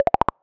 menu-freeplay-click.wav